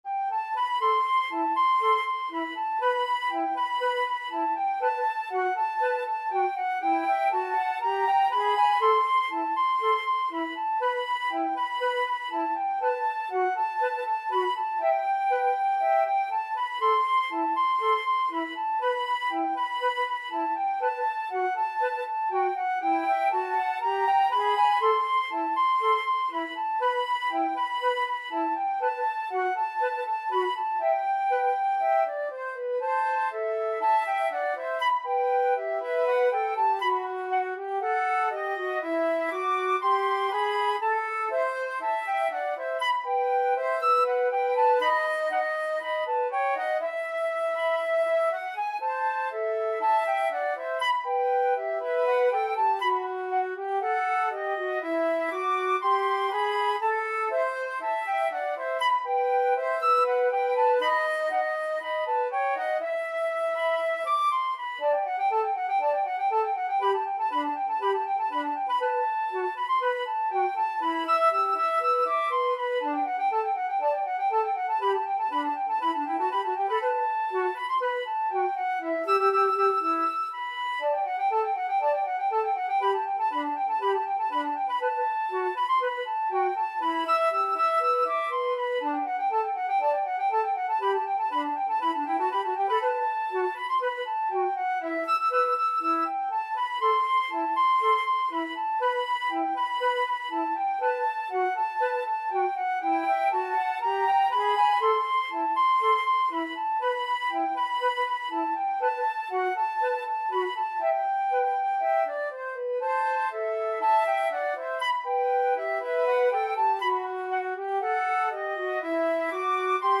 Flute 1Flute 2
2/2 (View more 2/2 Music)
Fast Two in a Bar =c.120
Traditional (View more Traditional Flute Duet Music)